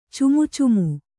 ♪ cumucumu